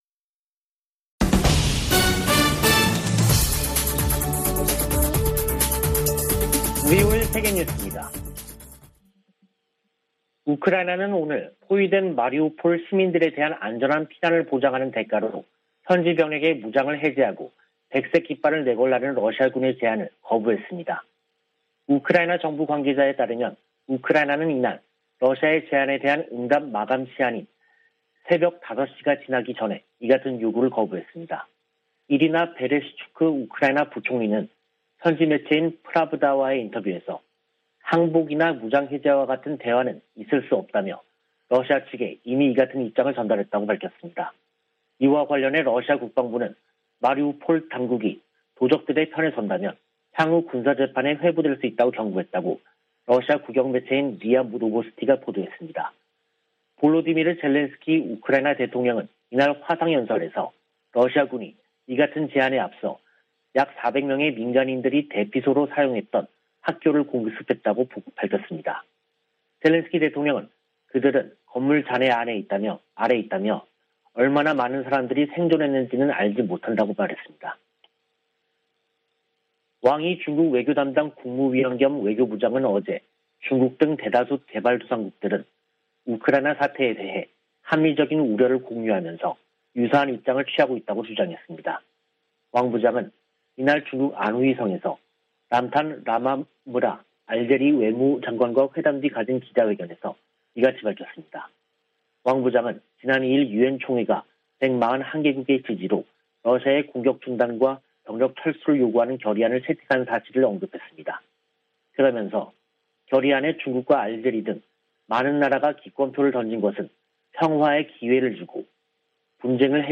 VOA 한국어 간판 뉴스 프로그램 '뉴스 투데이', 2022년 3월 21일 2부 방송입니다. 북한이 20일 한반도 서해상으로 방사포로 추정되는 단거리 발사체 4발을 쐈습니다. 미 국무부는 북한이 미사일 도발 수위를 높이는데 대해 모든 필요한 조치를 취할 것이라며 본토와 동맹국 방어 의지를 재확인했습니다. 북한이 올해도 핵과 미사일 현대화 노력을 지속하며 다양한 시험 등을 고려할 수 있다고 미 국방정보국장이 전망했습니다.